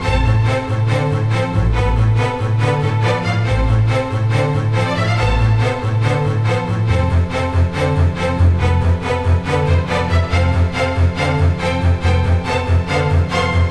可怕怪异激烈噪声重叠循环
描述：可怕怪异激烈噪声重叠循环。 非常强烈。 这个示例无缝循环。 使用Zoom H2记录，Audacity编辑和混合。
标签： 说话 恐怖 闹鬼 循环 妄想 男性 环境音 激烈 可怕的 主办 耳语 可怕 怪异 赞美 梦想 震颤 噪声 恶梦
声道立体声